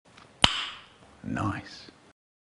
Nice Sound Effect: Unblocked Meme Soundboard